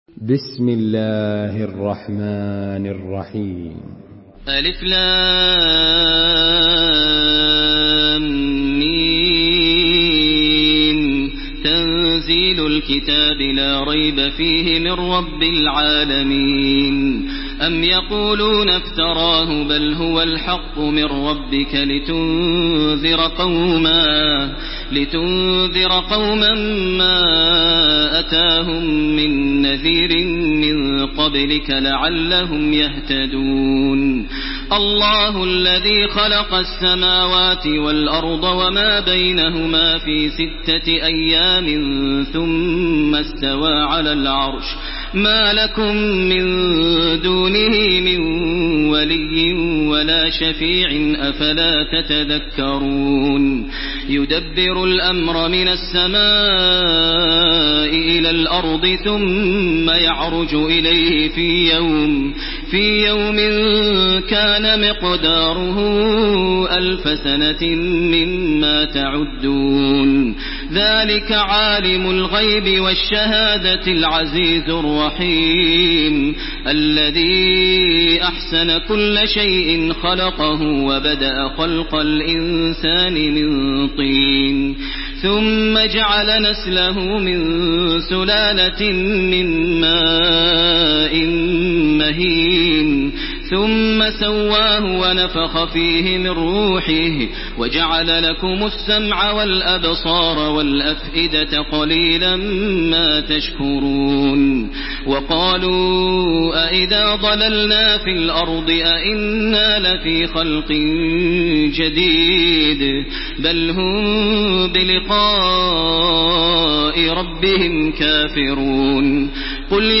Makkah Taraweeh 1429
Murattal